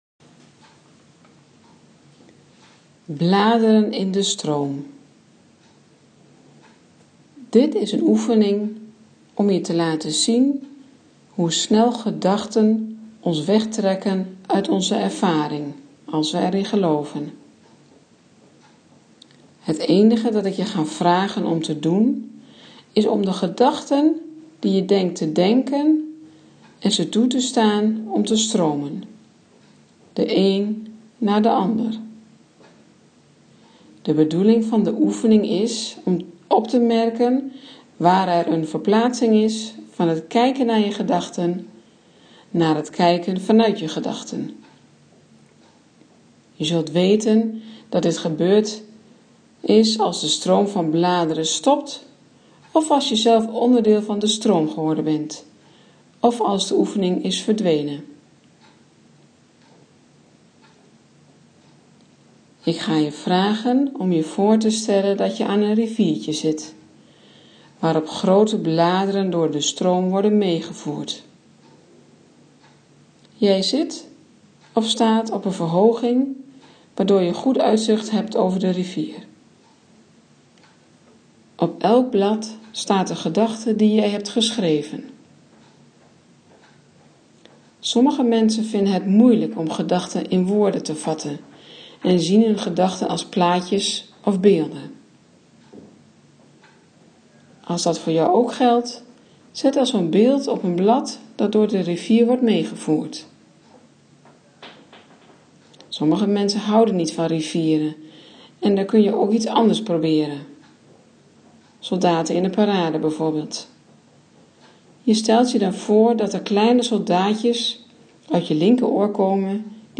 Oefening